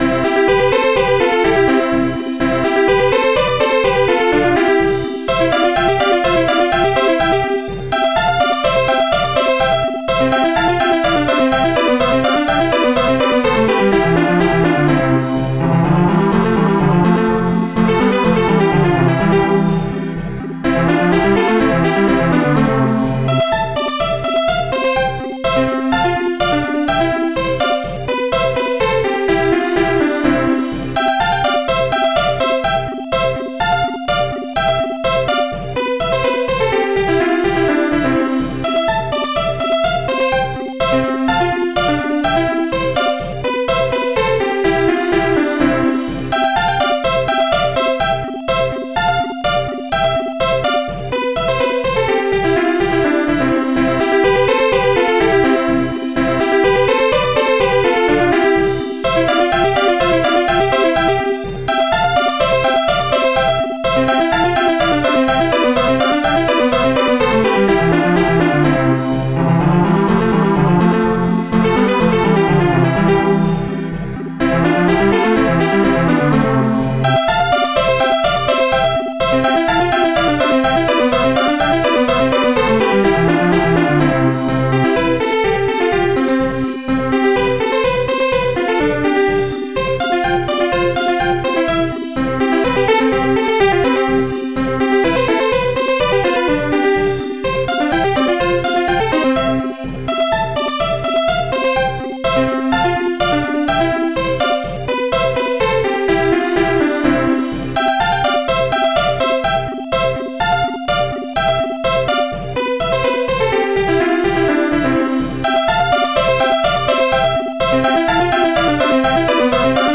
OctaMED Module
Piano